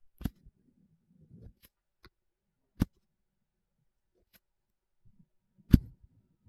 • anti static vinyl brush perc.wav
Immerse yourself in the distinct, crisp sound of an anti-static vinyl brush.
anti_static_vinyl_brush_Krh.wav